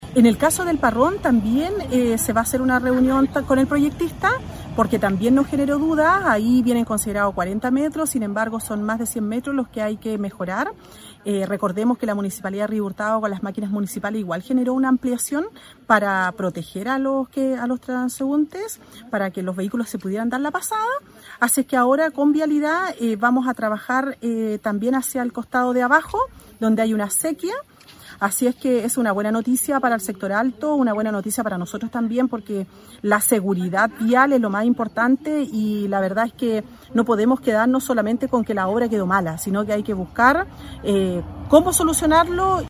La alcaldesa recalca que es un deber buscar soluciones ante estos problemas.